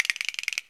LATIN LICK 1.wav